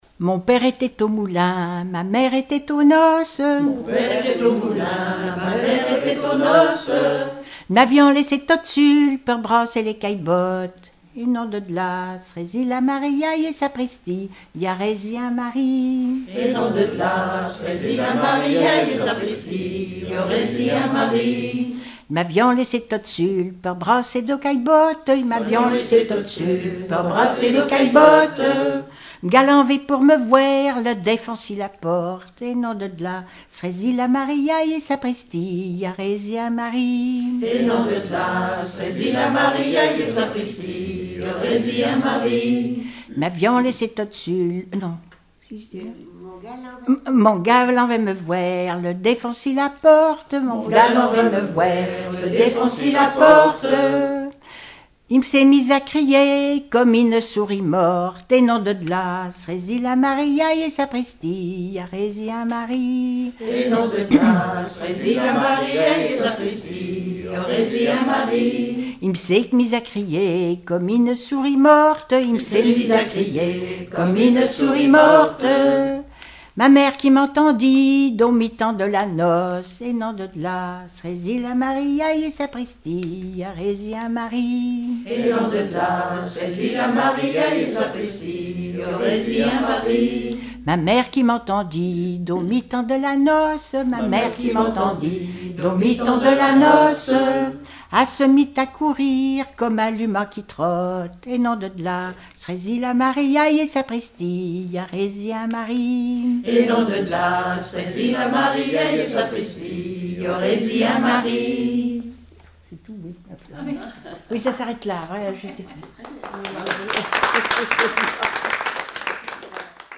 Mémoires et Patrimoines vivants - RaddO est une base de données d'archives iconographiques et sonores.
Collectif-veillée (2ème prise de son)
Pièce musicale inédite